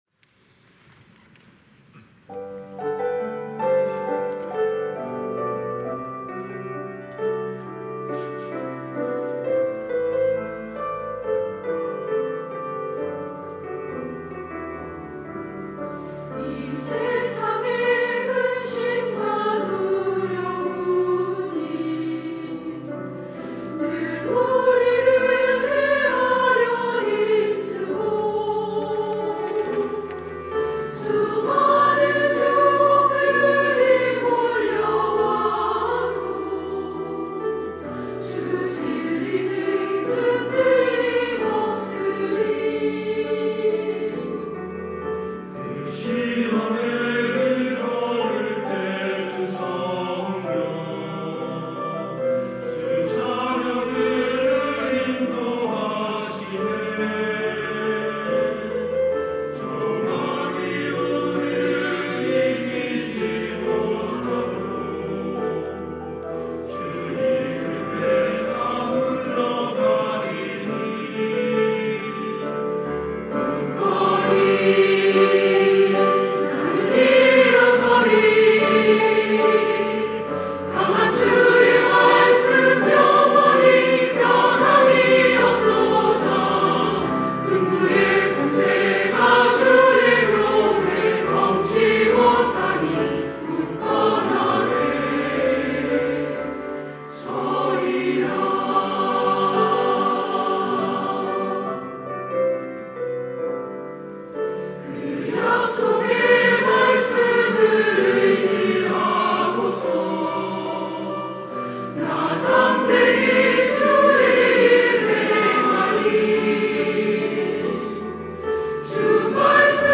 시온(주일1부) - 굳건히 서리라
찬양대